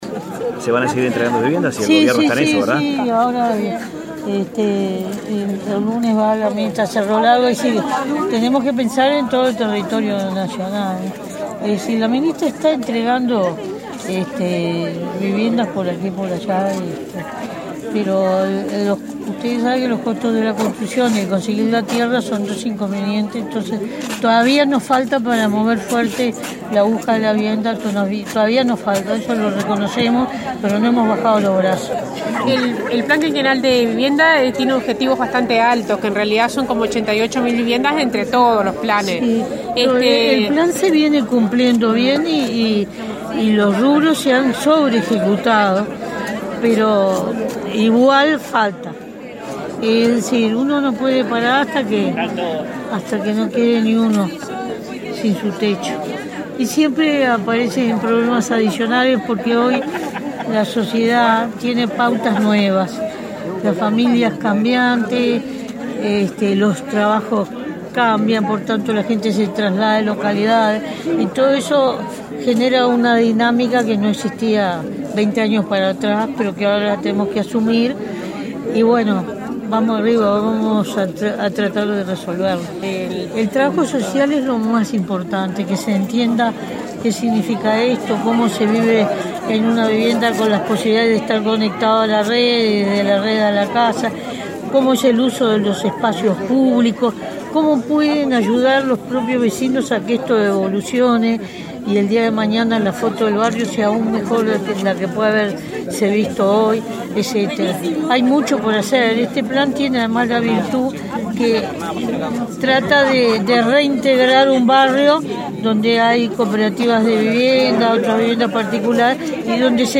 En diálogo con la prensa, valoró la importancia del trabajo social en la zona donde se construyen viviendas, al participar este viernes de la inauguración de 38 viviendas en Pando Norte.